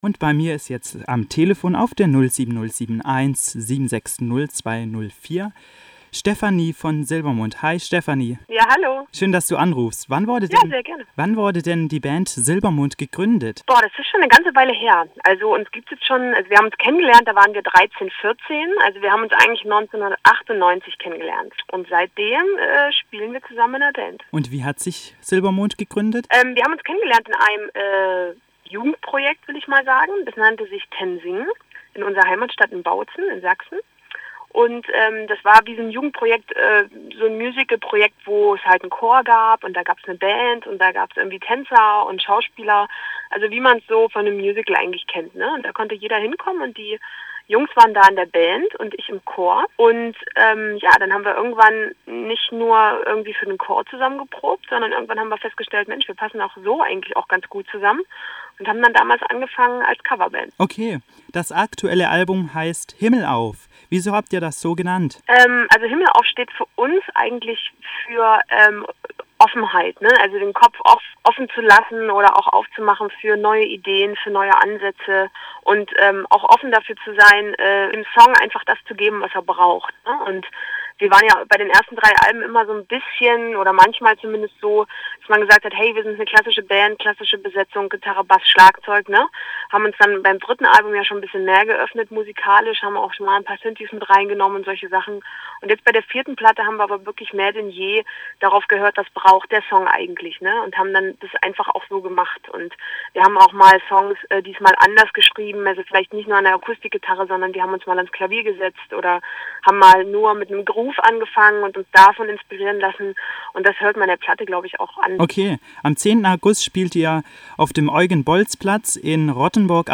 Stefanie Kloß von SILBERMOND im Interview